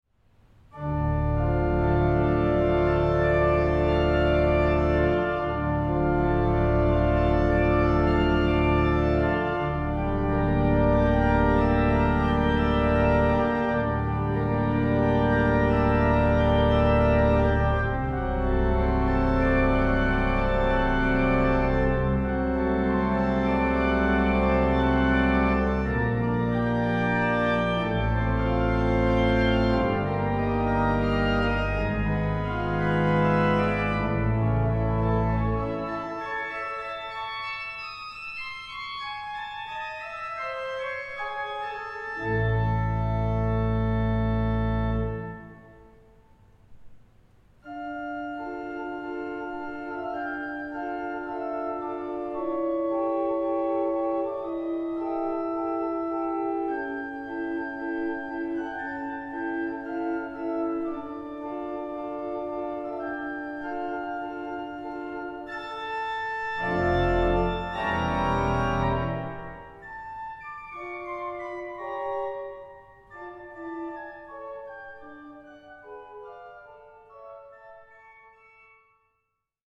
Konzert CD
WALCKER-Orgel von 1928.